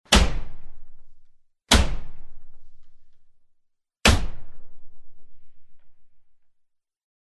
Звуки удара по голове
На этой странице собраны различные звуки ударов по голове – от мультяшных до максимально реалистичных.